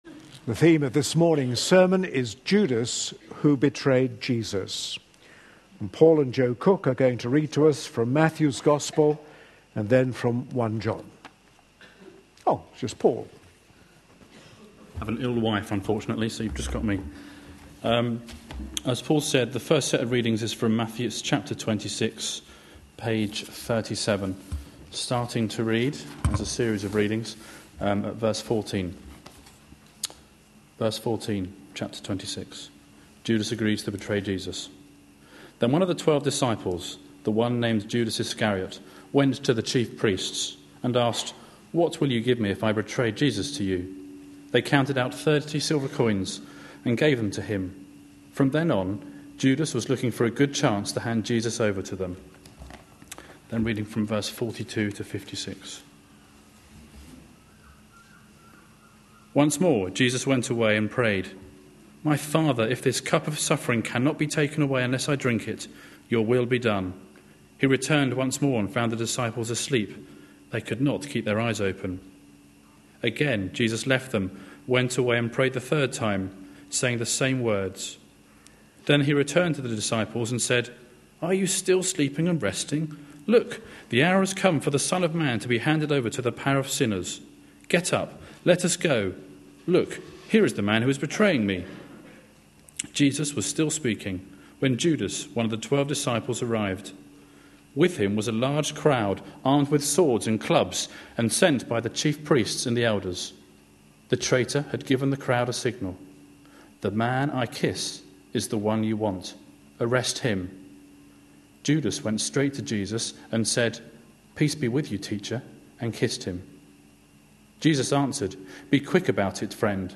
A sermon preached on 24th February, 2013, as part of our Passion Profiles and Places -- Lent 2013. series.